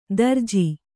♪ darji